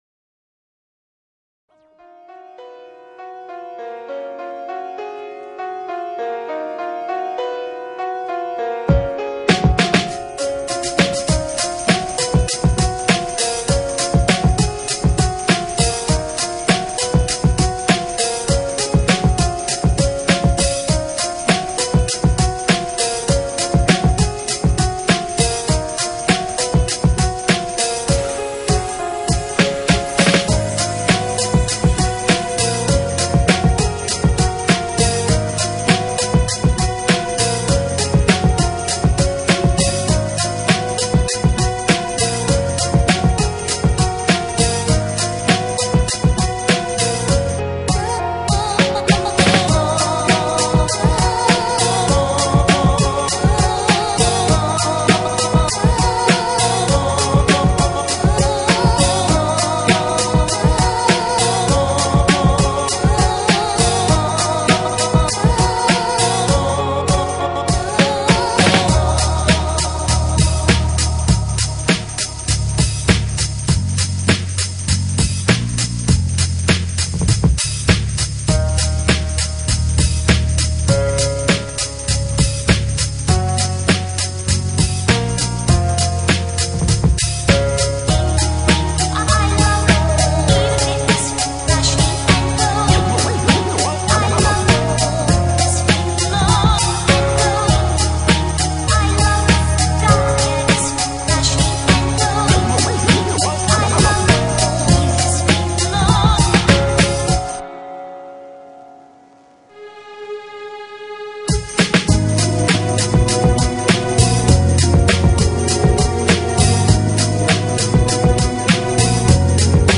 Sorry about the really low quality.